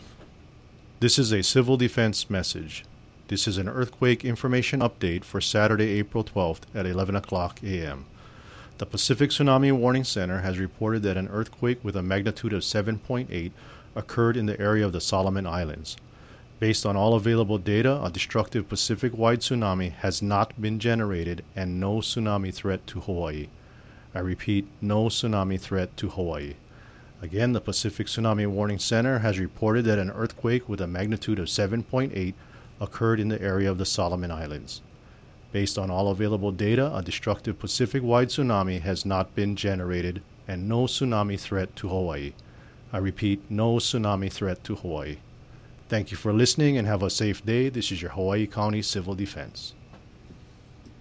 Hawaii County Civil Defense message